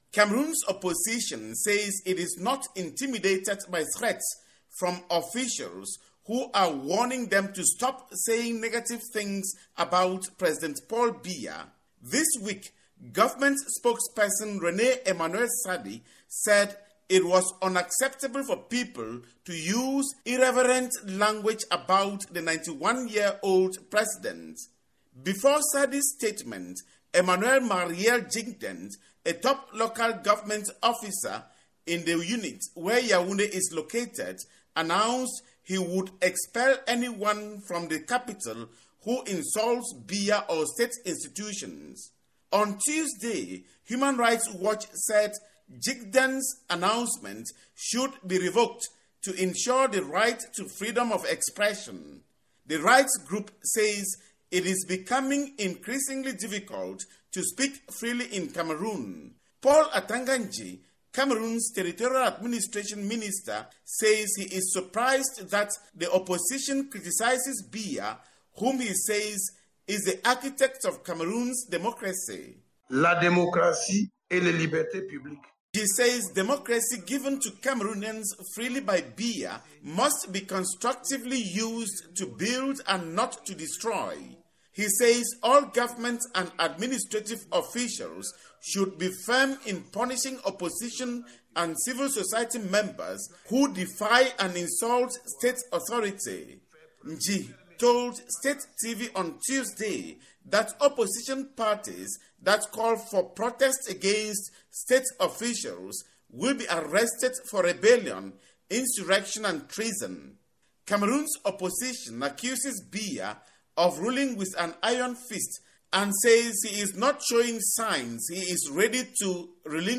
reports from Yaounde